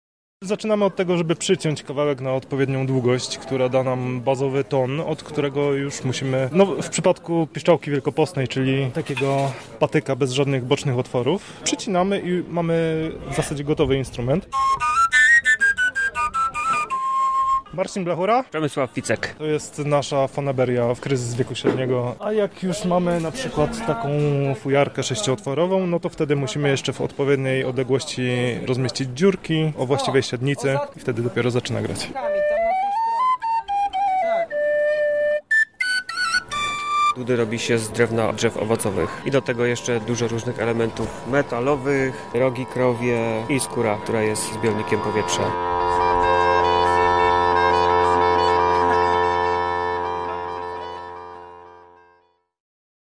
Wszystkiemu towarzyszy ludowa muzyka.